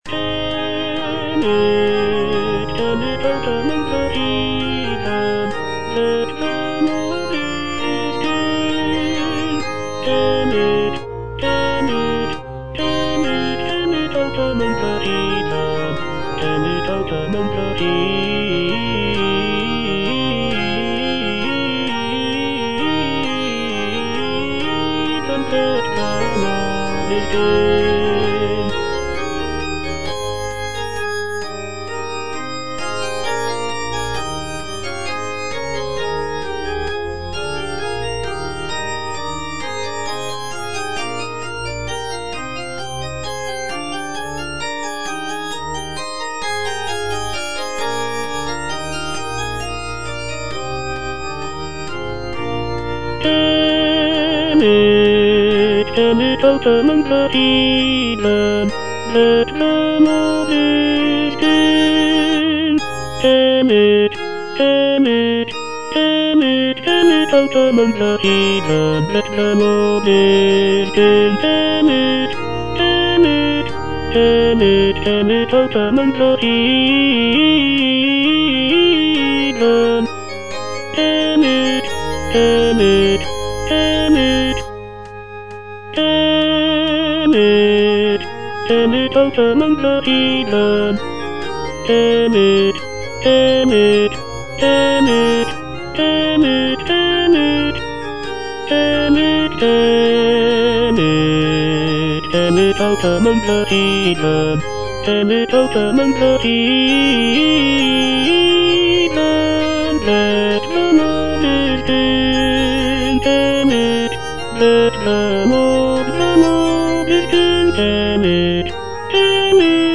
Choralplayer playing O come, let us sing unto the Lord - Chandos anthem no. 8 HWV253 (A = 415 Hz) by G.F. Händel based on the edition CPDL #09622
G.F. HÄNDEL - O COME, LET US SING UNTO THE LORD - CHANDOS ANTHEM NO.8 HWV253 (A = 415 Hz) Tell it out among the heathen - Tenor (Voice with metronome) Ads stop: auto-stop
It is a joyful and celebratory piece, with uplifting melodies and intricate harmonies.
The use of a lower tuning of A=415 Hz gives the music a warmer and more resonant sound compared to the standard tuning of A=440 Hz.